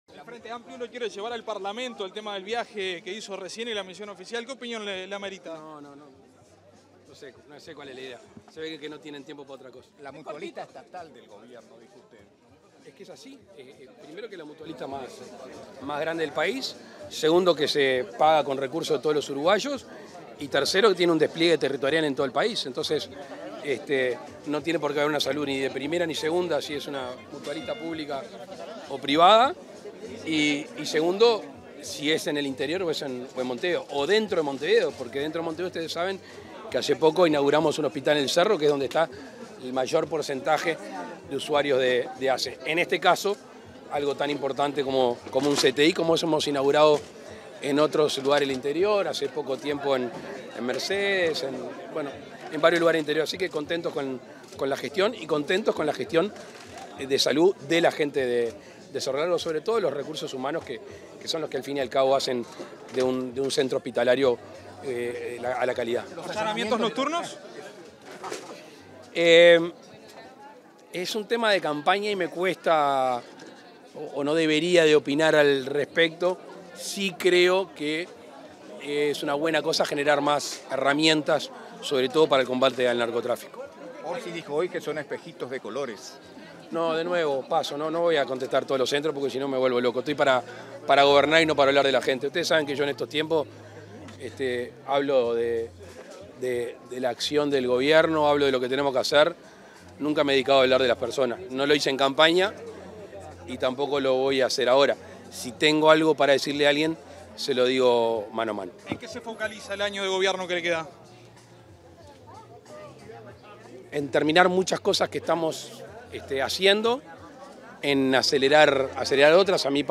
Declaraciones a la prensa del presidente de la República, Luis Lacalle Pou
Declaraciones a la prensa del presidente de la República, Luis Lacalle Pou 22/12/2023 Compartir Facebook X Copiar enlace WhatsApp LinkedIn Tras participar en la inauguración del centro de tratamiento intensivo del hospital de Melo, este 22 de diciembre, el presidente de la República, Luis Lacalle Pou, realizó declaraciones a la prensa.